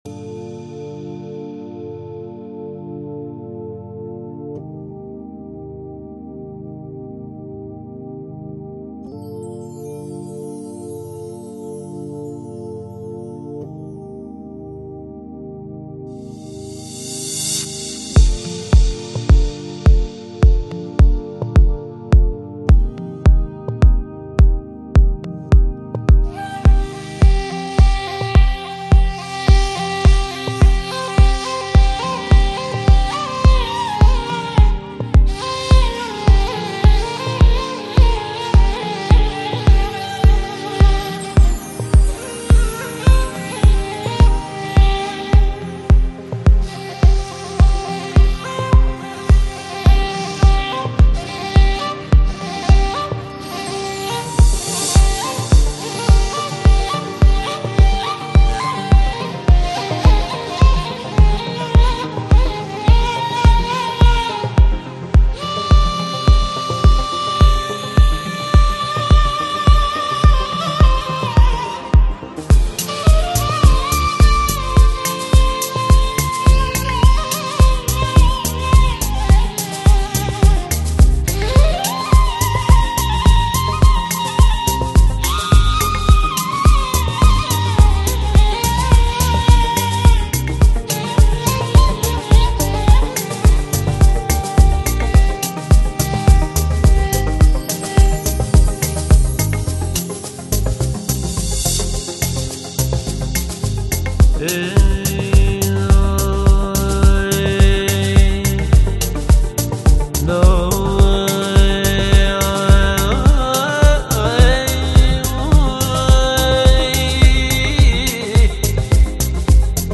Жанр: Chill Out, Downtempo, Organic House, Ethnic